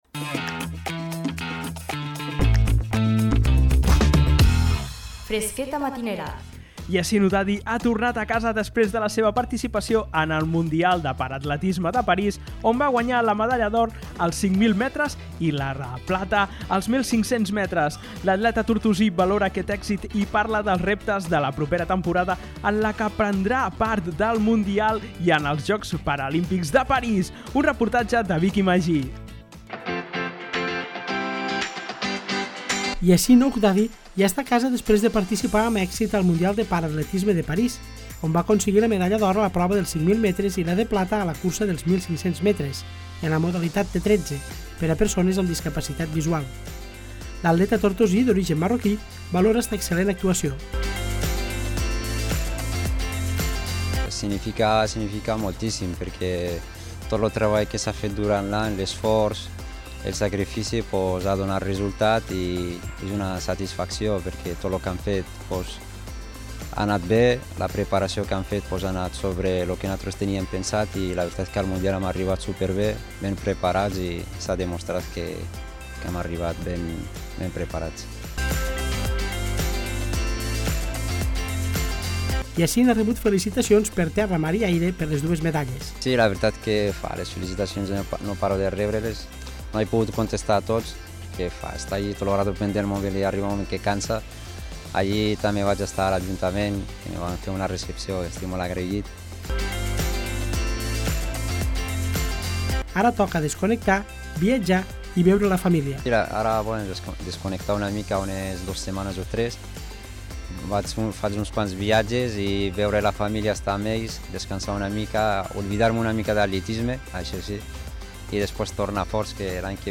Un reportatge